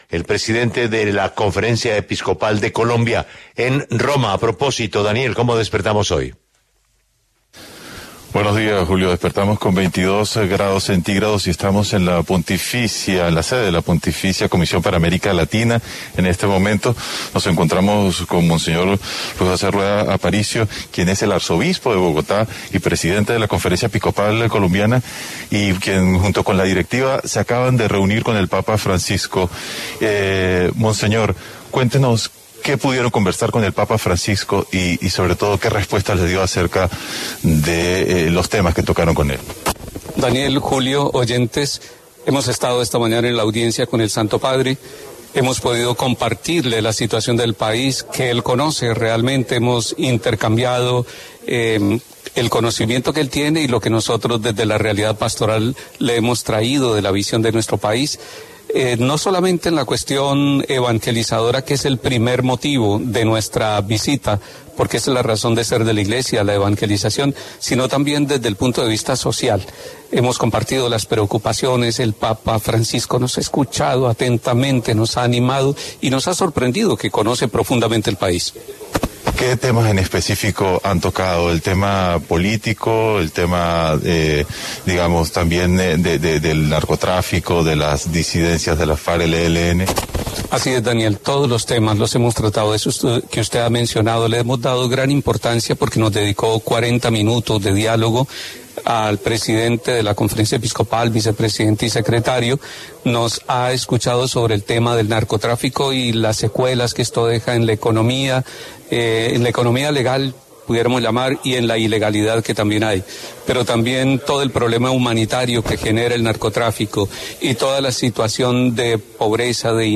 Monseñor Luis José Rueda Aparicio, arzobispo de Bogotá y presidente de la Conferencia Episcopal colombiana, habló en La W a propósito del encuentro de la Conferencia Episcopal en Roma.
A propósito de la audiencia entre directivos de la Conferencia Episcopal con el papa Francisco, La W conversó con monseñor Luis José Rueda Aparicio, arzobispo de Bogotá y presidente de la organización, desde la rueda de prensa en la Pontificia de la Comisión para América Latina en la Vía della Conciliazione del Vaticano.
En el encabezado escuche la entrevista completa con monseñor Luis José Rueda Aparicio, arzobispo de Bogotá y presidente de la Conferencia Episcopal colombiana.